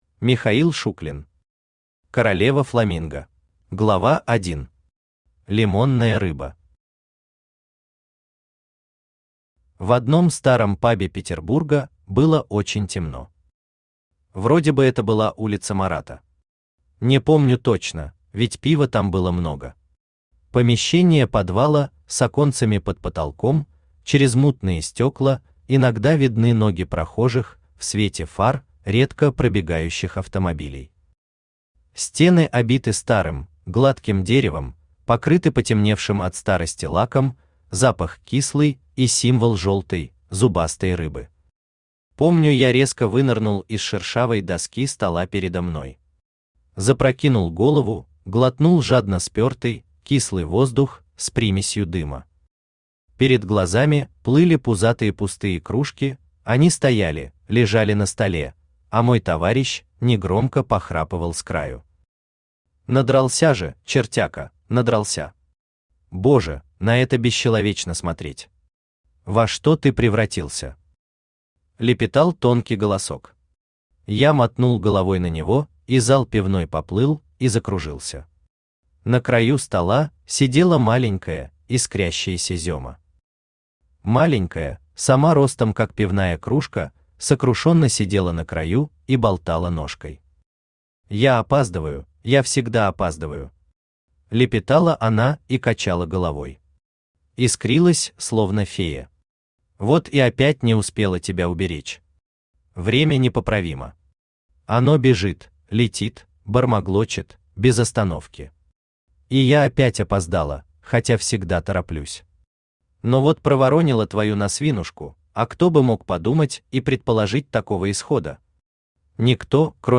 Аудиокнига Королева фламинго | Библиотека аудиокниг
Aудиокнига Королева фламинго Автор Михаил Шуклин Читает аудиокнигу Авточтец ЛитРес.